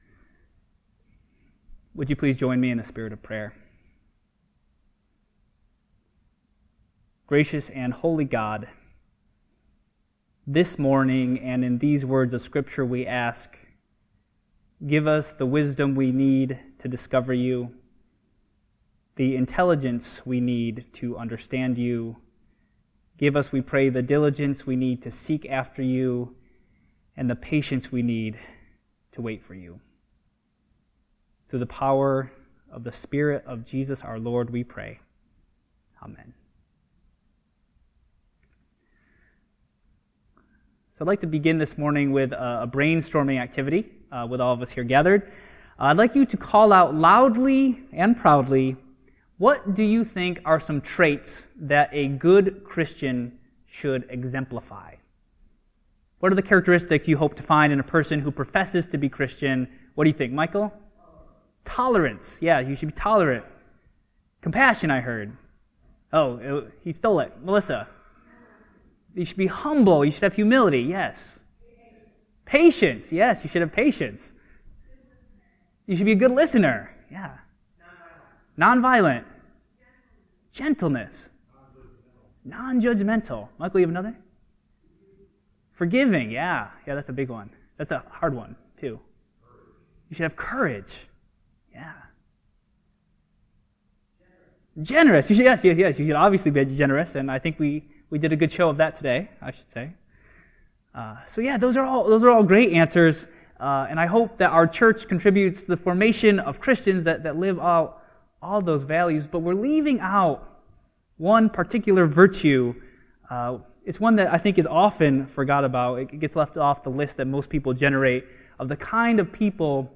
A message from the series "Narrative Lectionary."